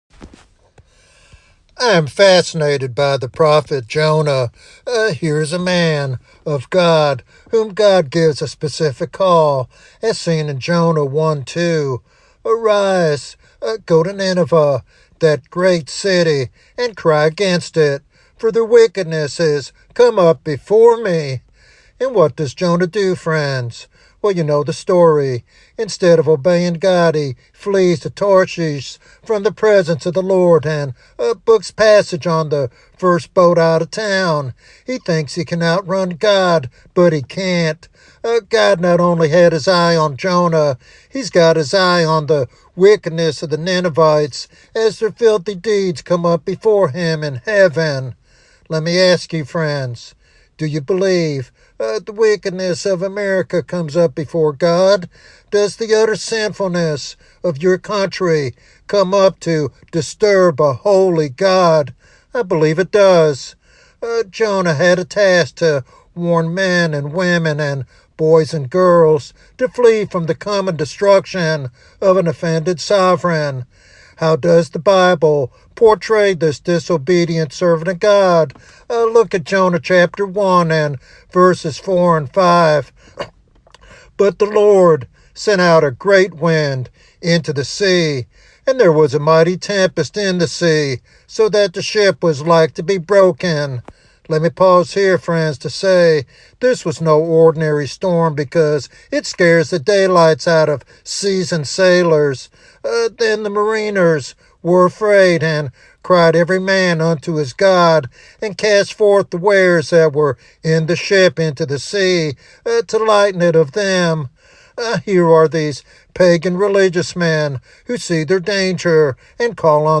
This sermon challenges the church to confront societal wickedness boldly and seek revival before judgment comes.